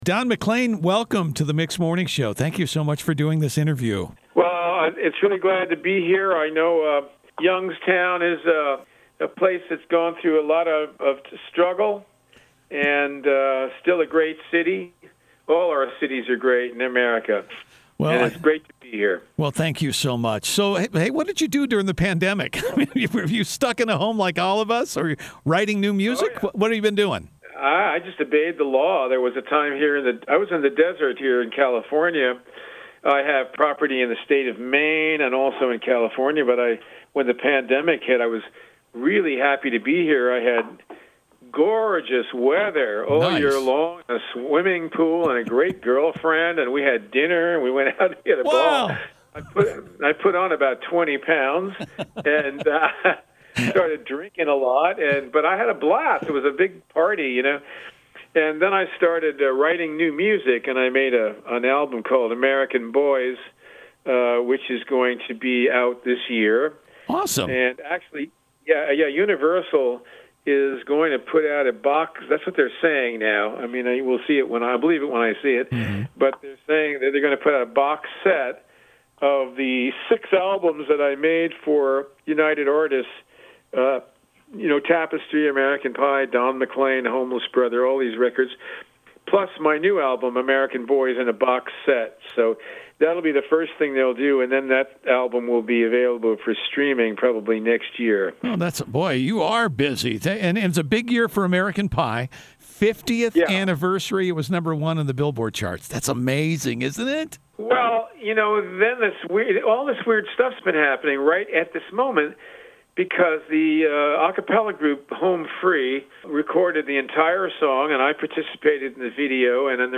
Don McLean Talks About the 50th Anniversary of “American Pie”